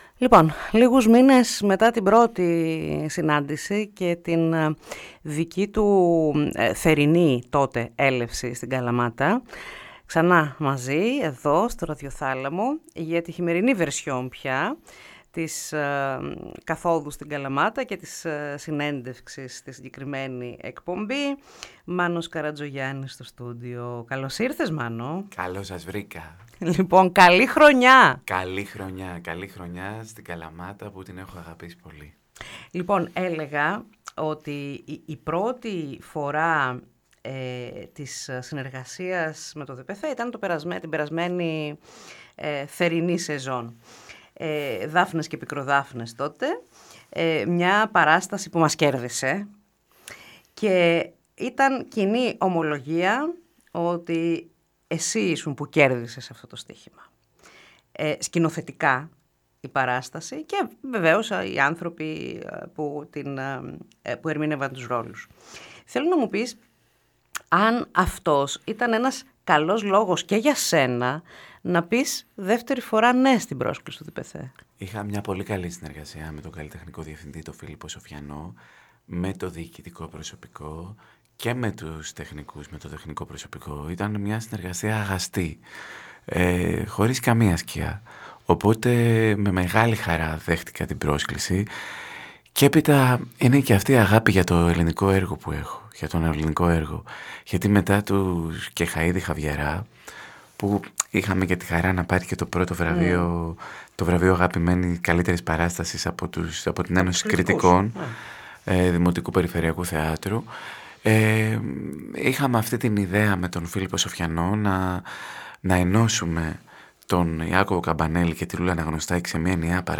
ΣΥΝΕΝΤΕΥΞΗ